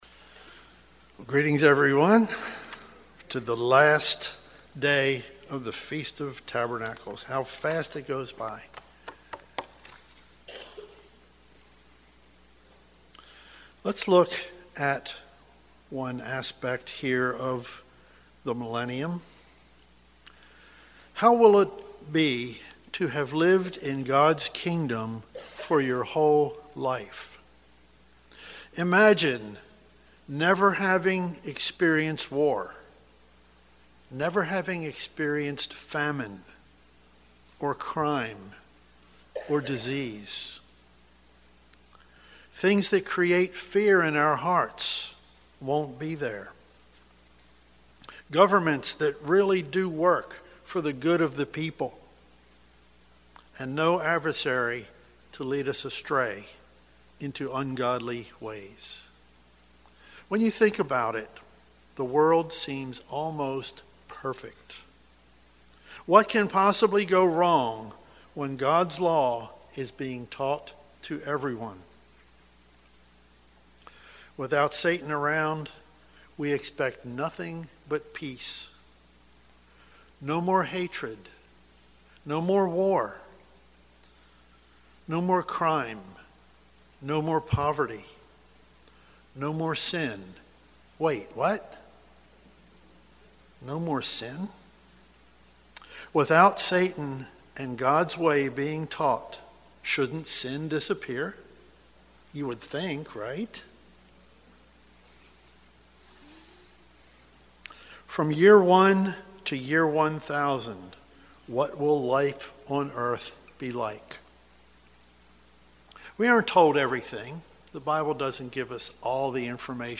This sermon was given at the Ocean City, Maryland 2023 Feast site.